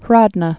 (hrôdnə) or Grod·no (grôdnō, -nə)